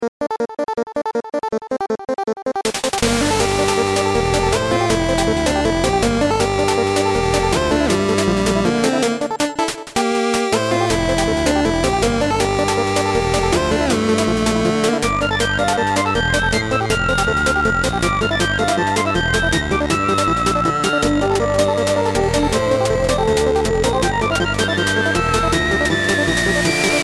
Short battle theme
mystical.mp3